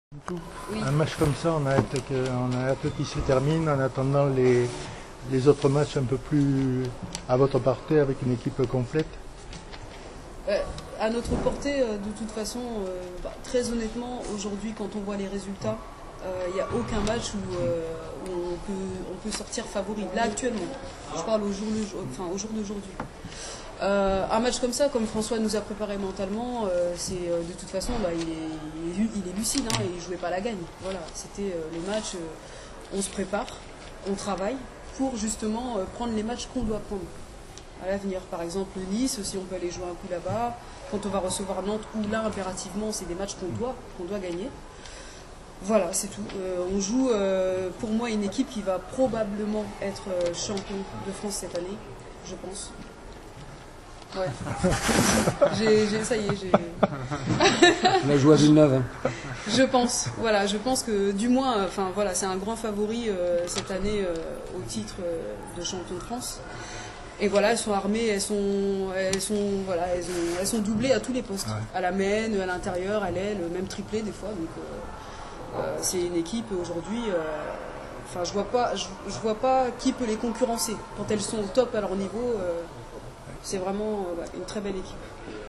Conférence de presse